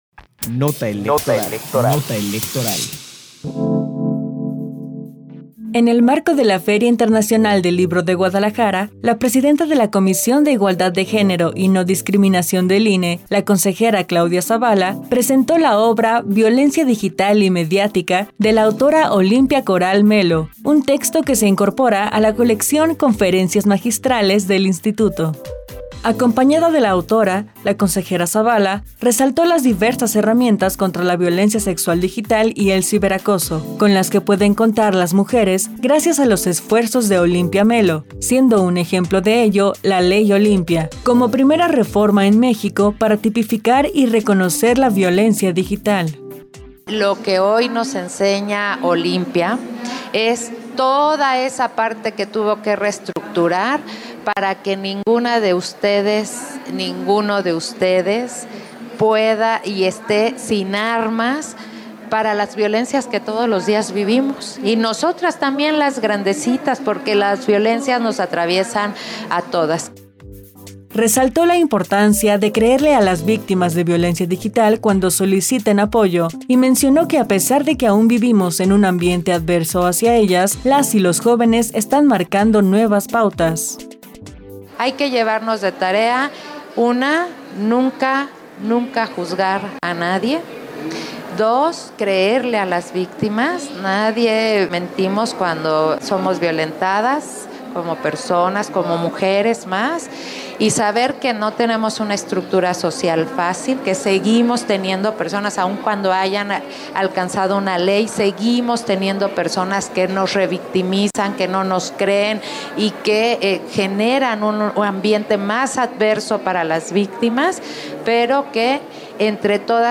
Nota de audio sobre la participación de la consejera electoral, Claudia Zavala en la FIL Guadalajara, 3 de diciembre de 2024